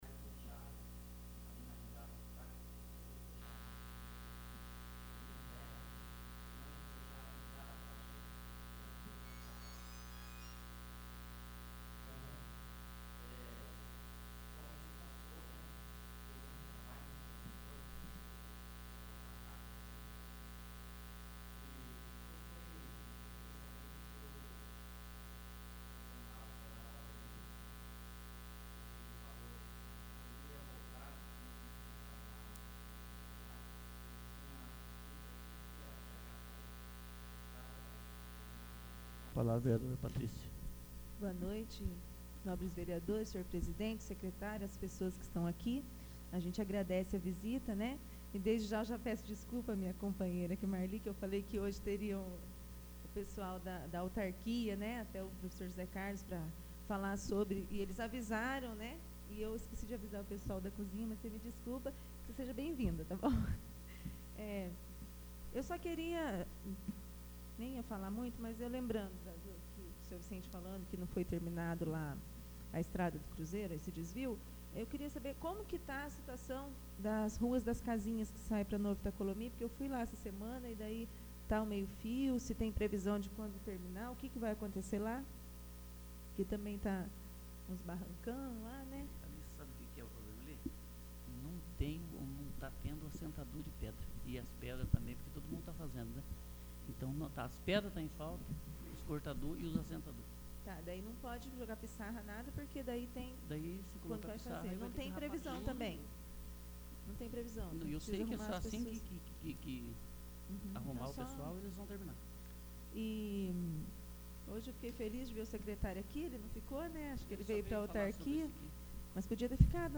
28º. Sessão Ordinária